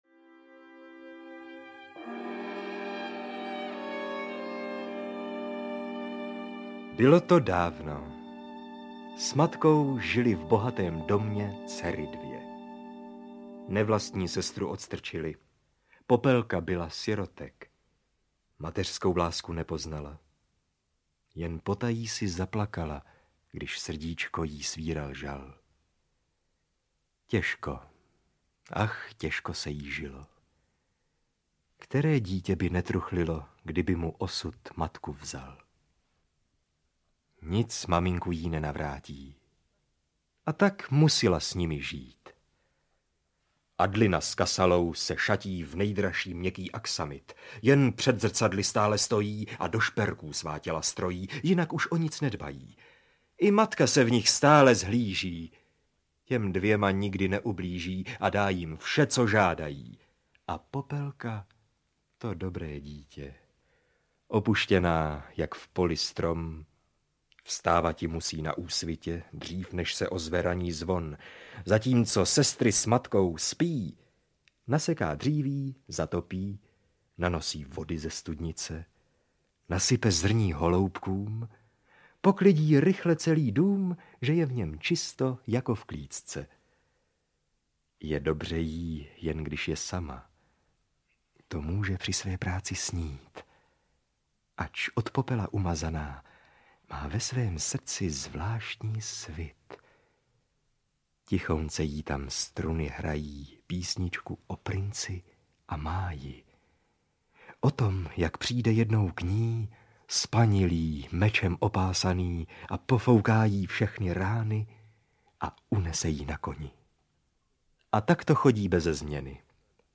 Audio knihaAlbum pohádek
Ukázka z knihy